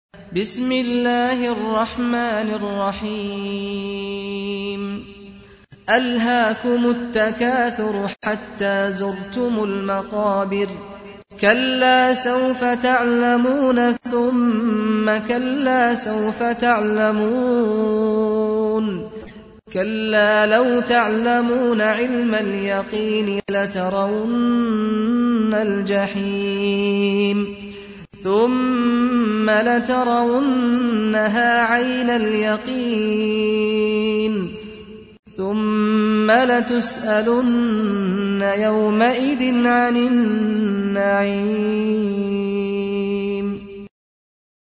تحميل : 102. سورة التكاثر / القارئ سعد الغامدي / القرآن الكريم / موقع يا حسين